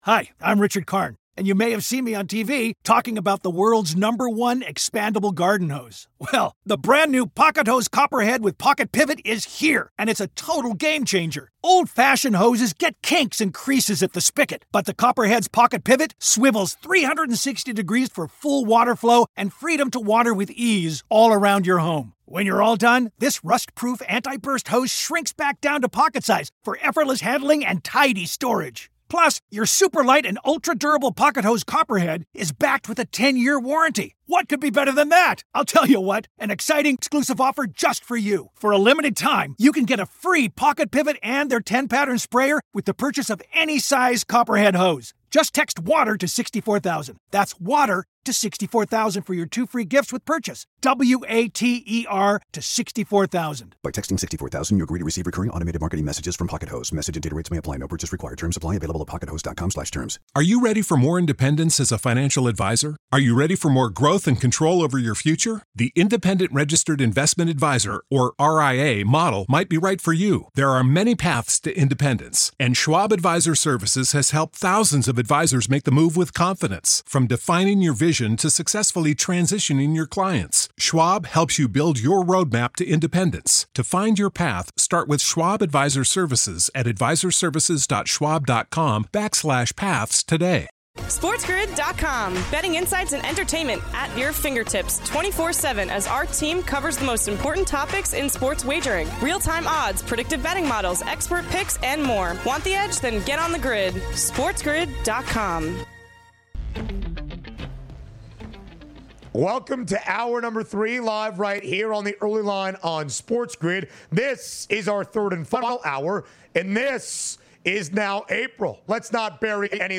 4/3 Hour 3: Jon Rothstein Interview, NBA Best Bets, MLB Game Previews, & More